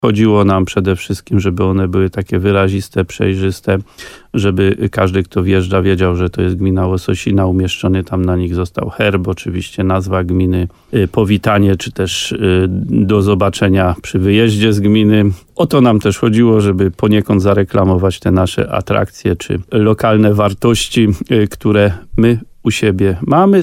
mówi wójt Adam Wolak